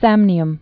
(sămnē-əm)